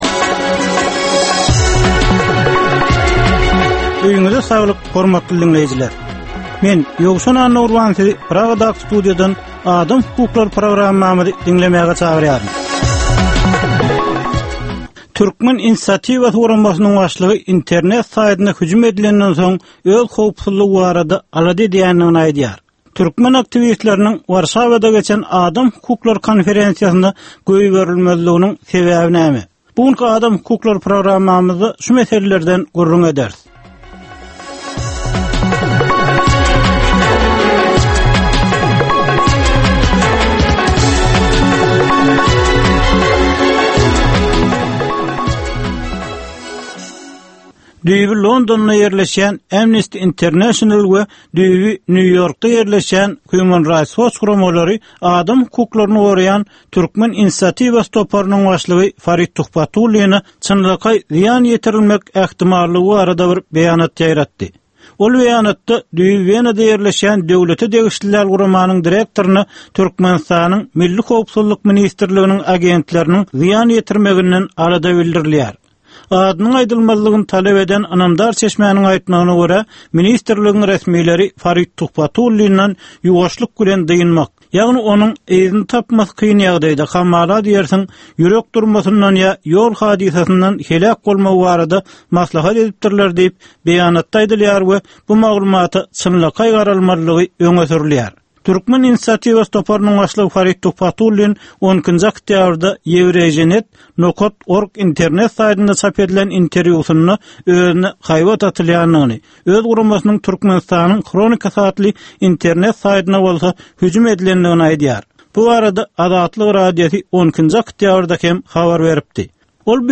Türkmenistandaky adam hukuklarynyň meseleleri barada ýörite programma. Bu programmada adam hukuklary bilen baglanyşykly anyk meselelere, problemalara, hadysalara we wakalara syn berilýär, söhbetdeşlikler we diskussiýalar gurnalýar.